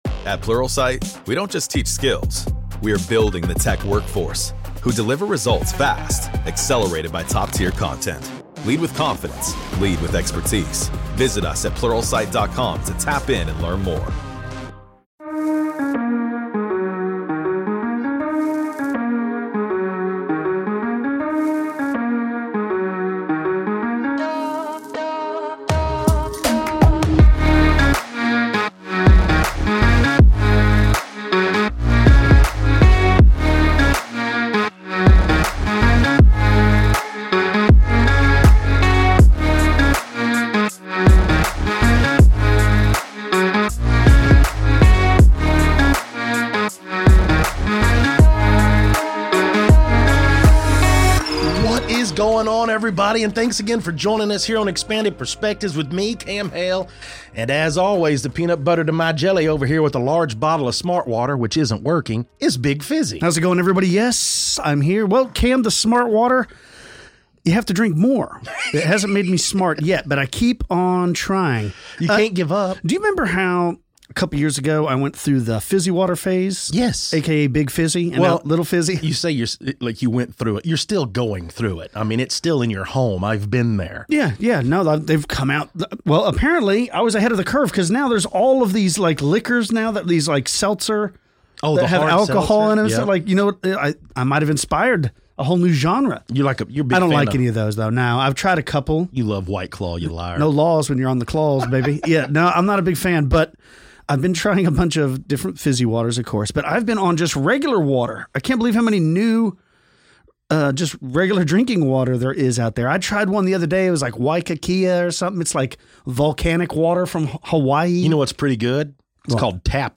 Then they get into some news where a listener from Florida calls in to describe a strange Orb she and some friends saw while out camping.